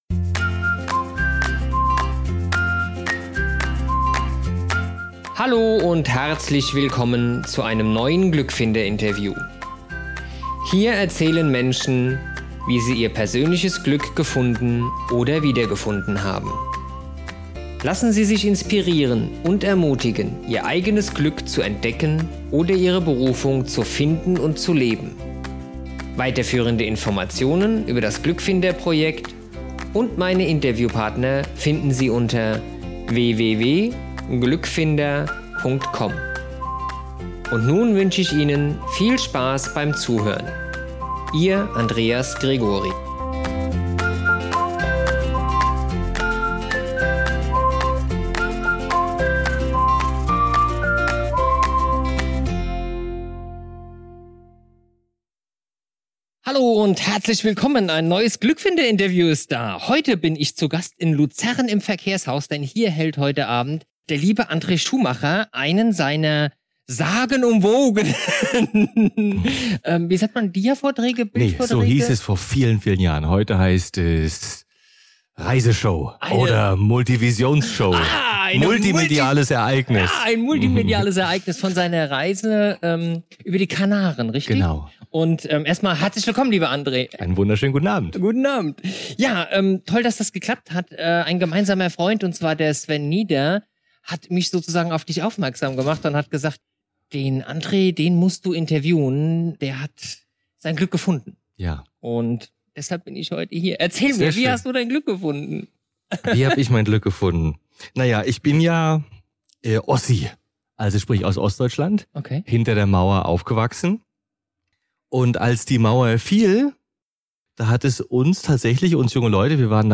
Interview
In diesem Interview spreche ich mit